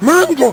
hitsound_retro5.wav